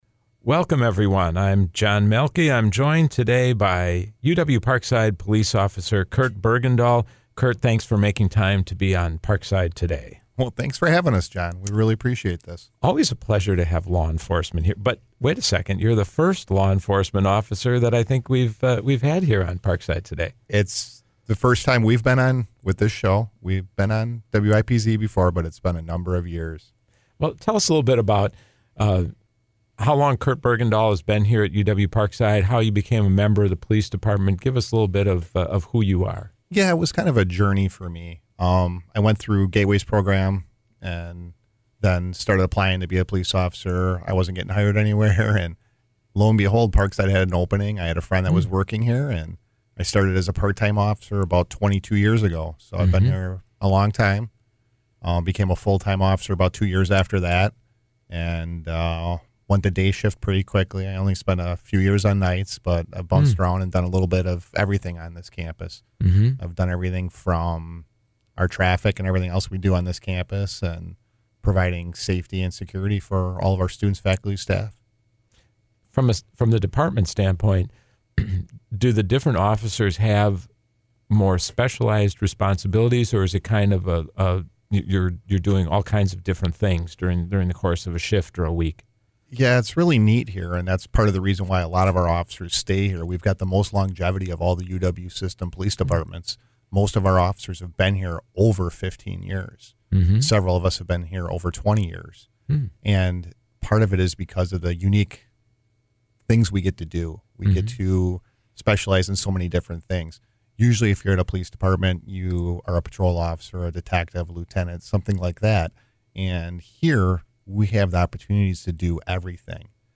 This interview originally aired on Tuesday, August 28, at 4 p.m. on WIPZ 101.5 FM.